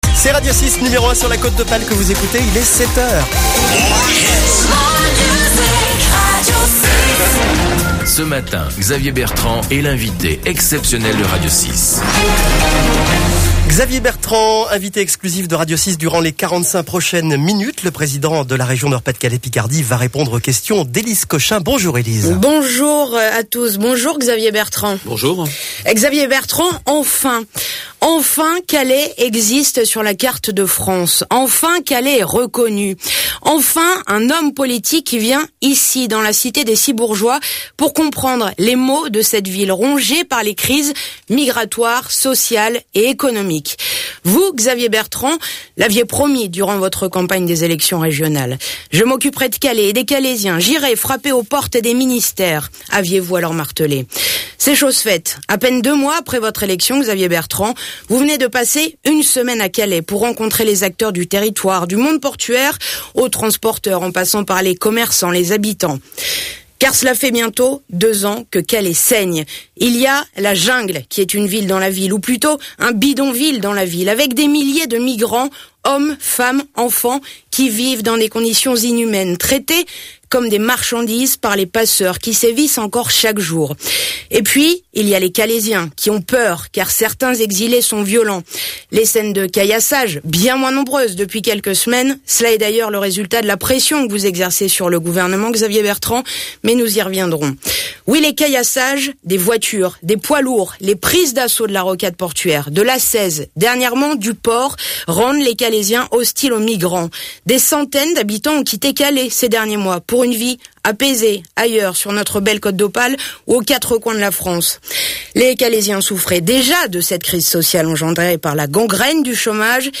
Retrouvez l'intégralité de l'interview de Xavier Bertrand ce vendredi matin sur RADIO 6